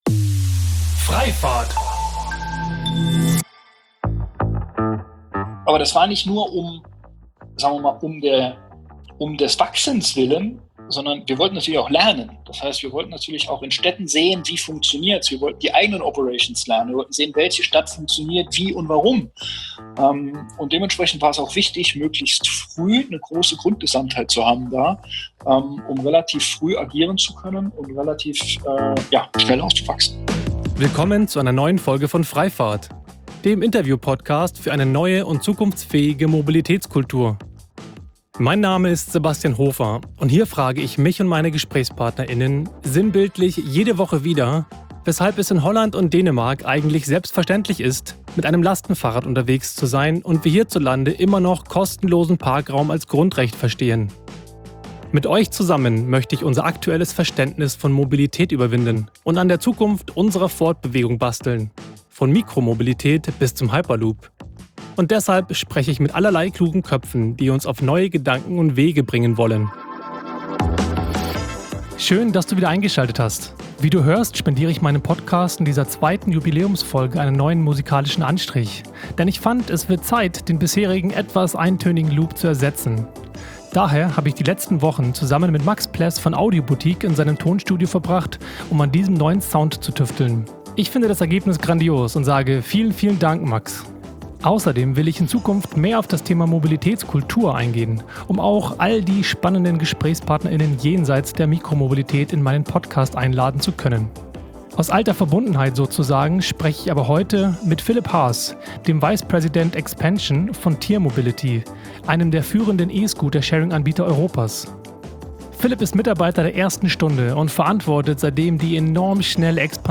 Wir diskutieren über e-Scooter, ein Jahr nach ihrer Ausrollung auf deutschen Straßen und was sich in Sachen Akzeptanz, Integration mit anderen Angeboten und Infrastruktur getan hat.